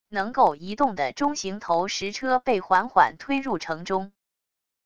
能够移动的中型投石车被缓缓推入城中wav音频